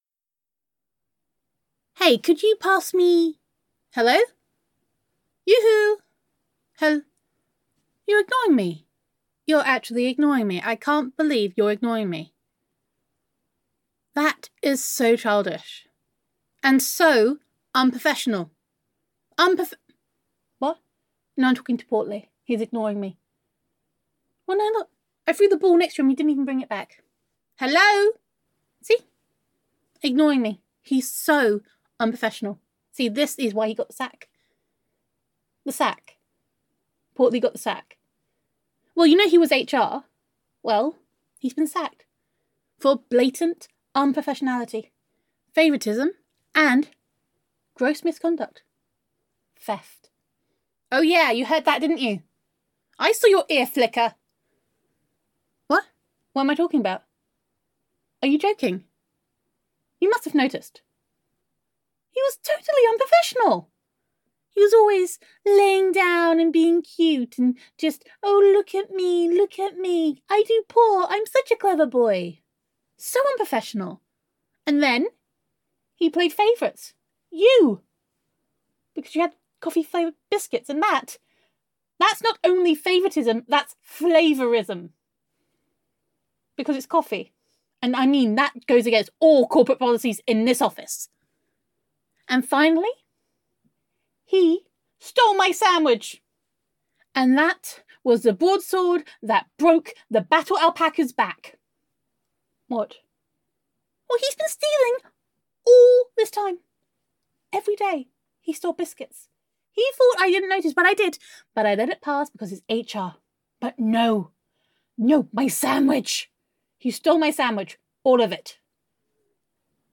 [F4A] Day Three - The Interview [Home With Honey][Girlfriend Roleplay][Self Quarantine][Domestic Bliss][Gender Neutral][Self-Quarantine With Honey]